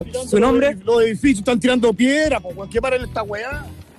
Fue en ese contexto que uno de los conductores acusó que estaban “tirando piedras” desde los edificios.